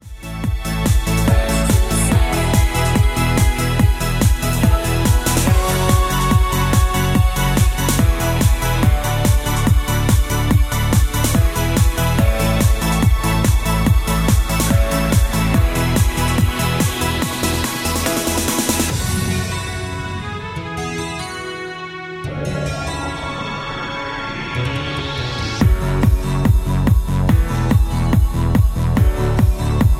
F#
MPEG 1 Layer 3 (Stereo)
Backing track Karaoke
Pop, Country, 2000s